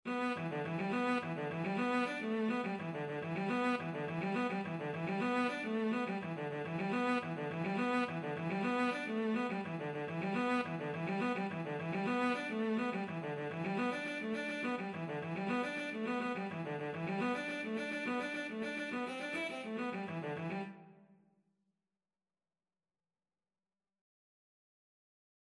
E minor (Sounding Pitch) (View more E minor Music for Cello )
6/8 (View more 6/8 Music)
Instrument:
Traditional (View more Traditional Cello Music)
Cello Sheet Music
Cello pieces in E minor